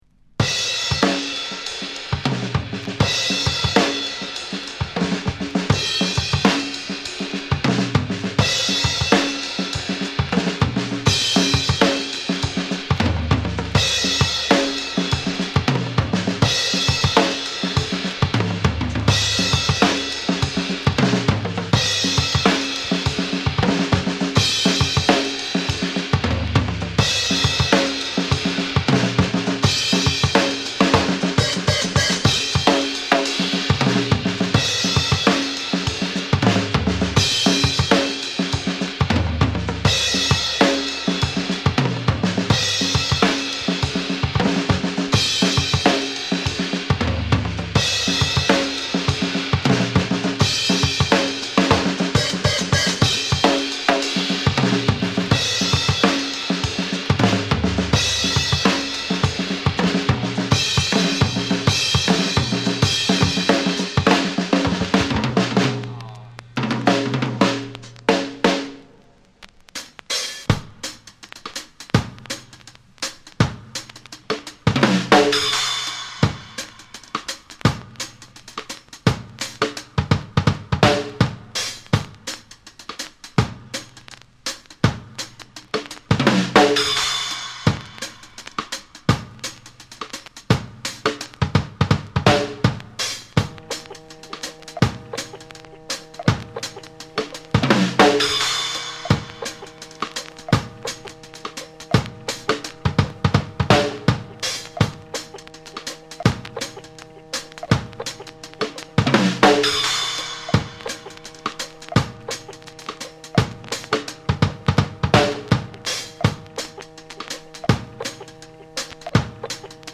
極めて前衛的でエクスペリメンタルな5トラック。